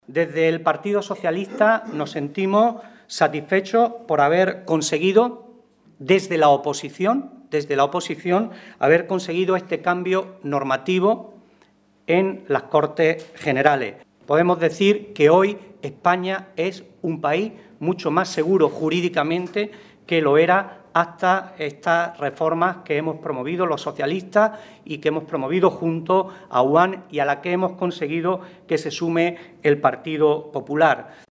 Rueda de prensa con AUAN